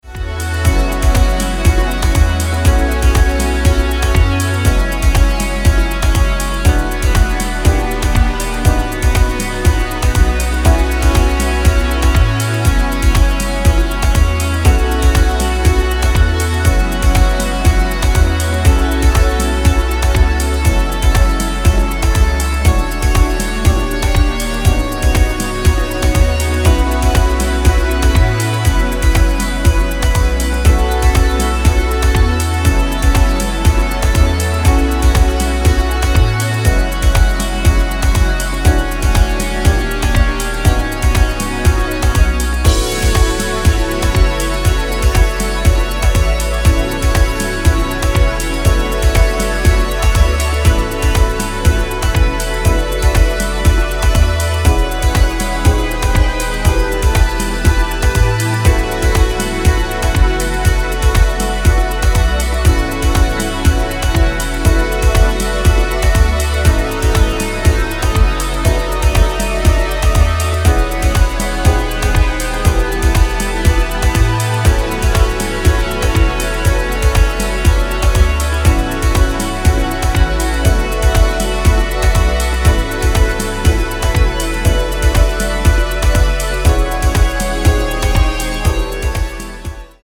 a piece of bliss warped in audio form